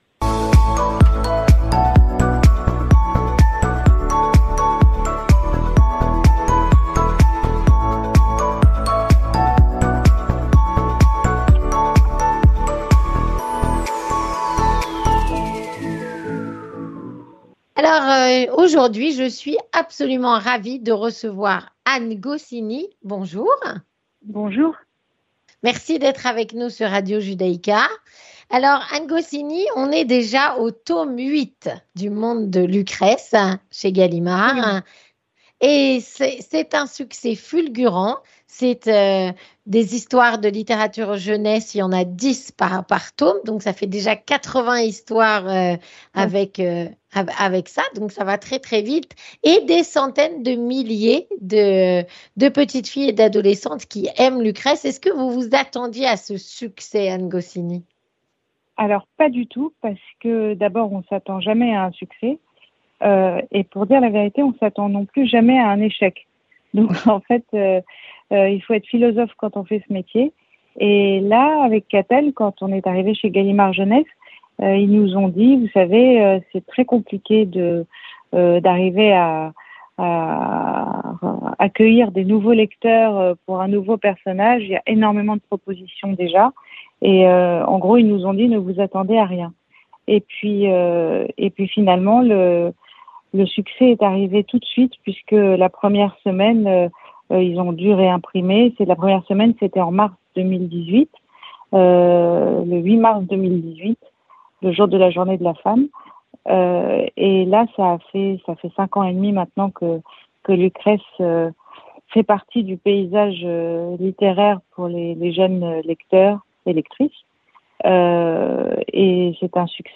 Rencontre - “Le monde de Lucrèce”.
Rencontre avec...Anne Goscinny, auteur de “Le monde de Lucrèce”